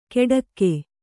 ♪ keḍakke